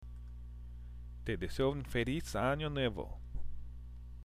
＜発音と日本語＞
（テ　デセオ　ウン　フェリス　アニョ　ヌエボ！）